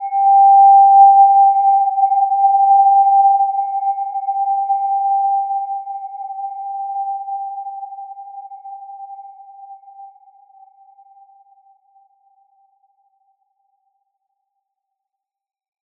Gentle-Metallic-4-G5-p.wav